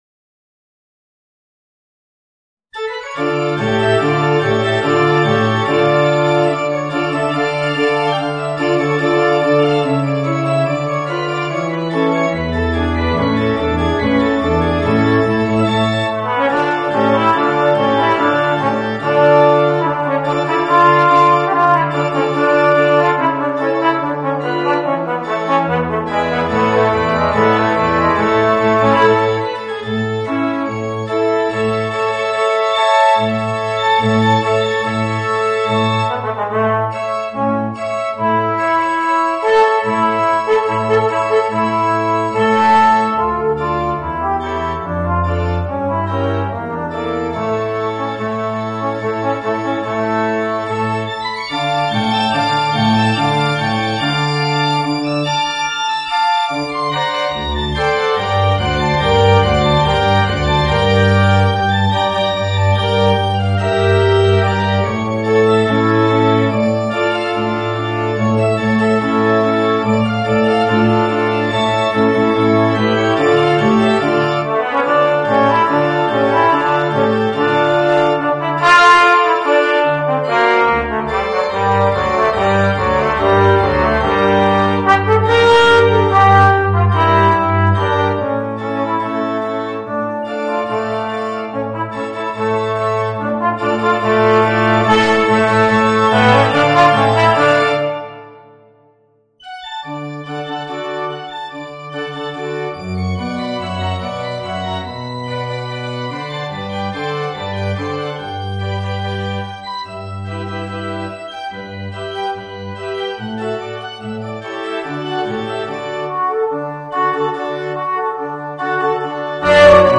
Voicing: Alto Trombone and Organ